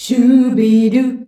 SCHUBIDU C.wav